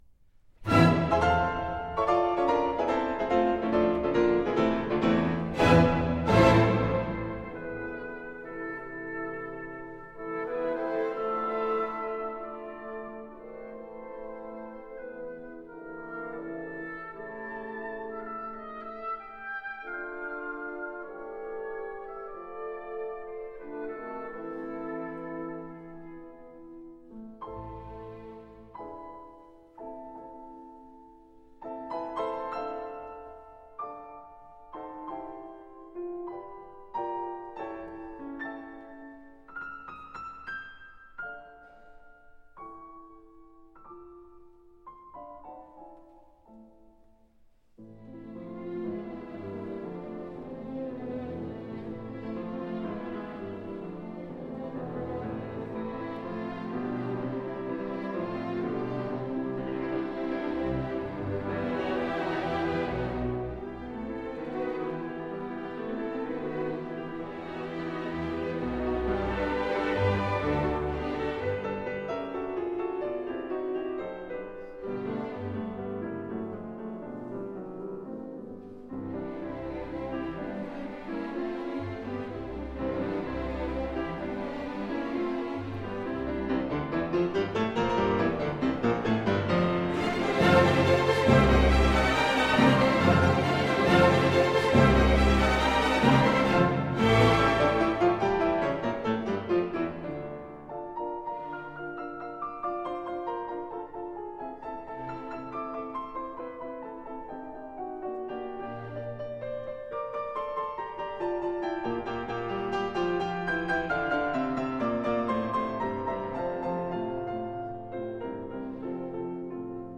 Subrepticement, la reprise à l’orchestre va permettre à la clarinette d’énoncer animato (animé) et piano espressivo une variation du thème initial qui deviendra le noyau du premier thème du troisième mouvement tandis que le piano accompagne cette variation de volubiles arpèges descendantes et poursuit sa route.
Quelques octaves brillantes du soliste amènent au tutti rayonnant de l’orchestre qui reprend forte le rappel insistant de ce motif.